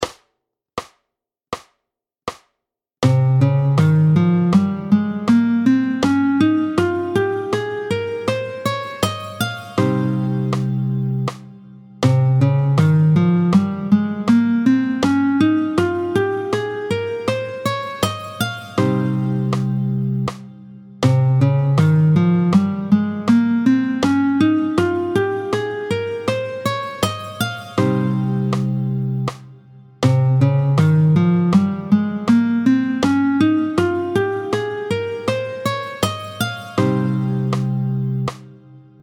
Le mode (et le doigté III) : do réb mib fa sol lab sib do est appelé le Phrygien.
27-03 Le doigté du mode de Do phrygien, tempo 80
27-03-Do-phrygien.mp3